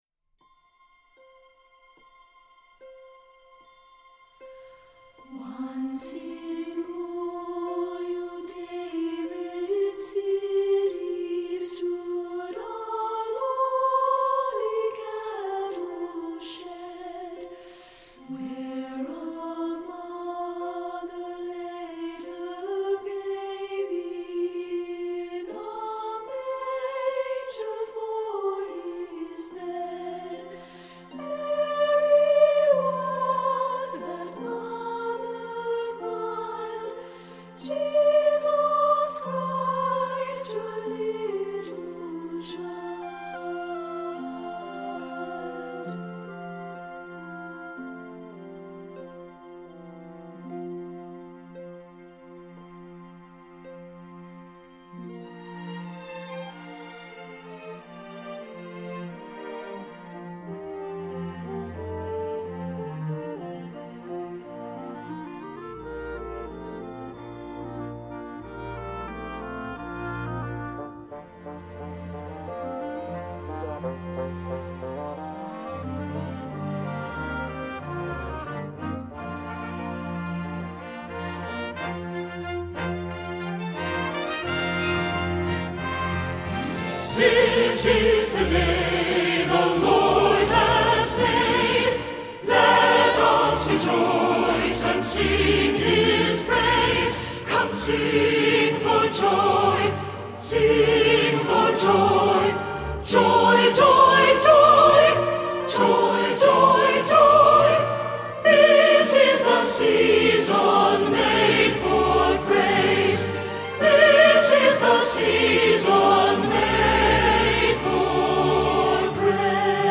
Choir Music | Belin Memorial UMC
Soprano: